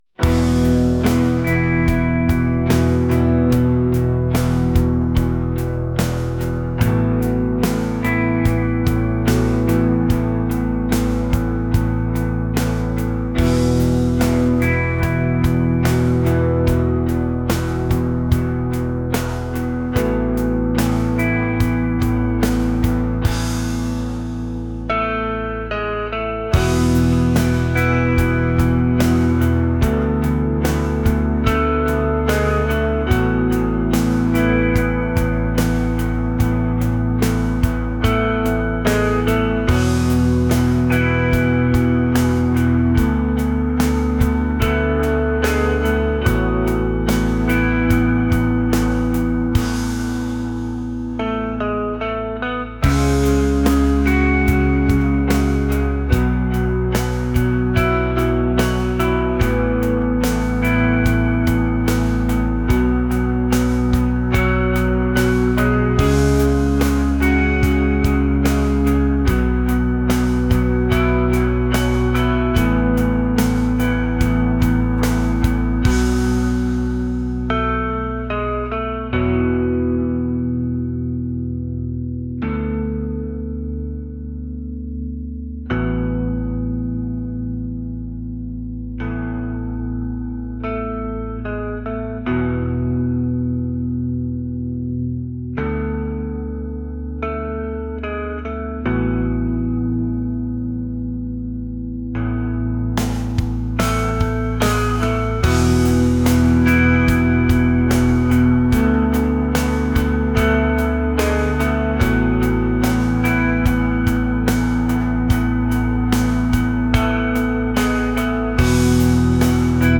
rock | acoustic | soul & rnb